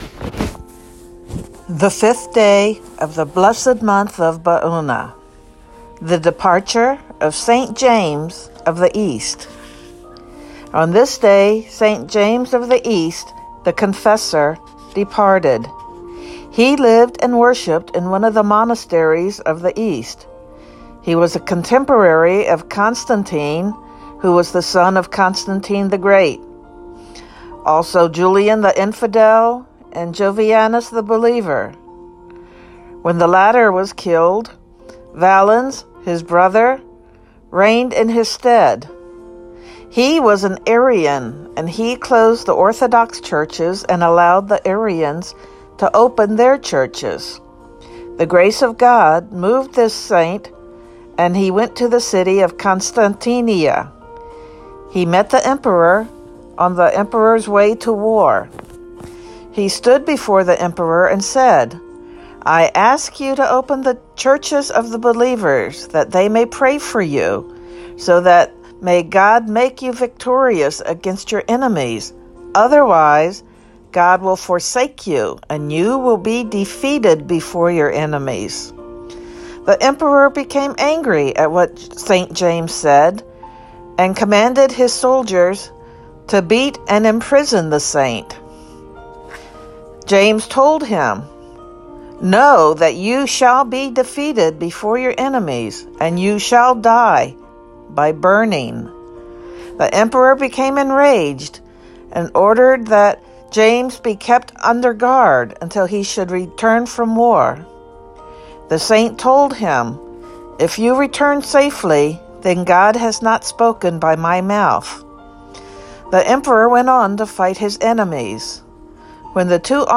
Synaxarium readings for the 5th day of the month of Baounah